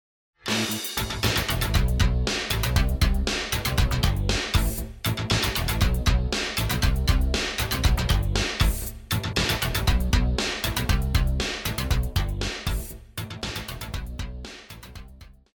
爵士,流行
套鼓(架子鼓)
乐团
演奏曲
放克音乐,流行音乐
独奏与伴奏
有主奏
有节拍器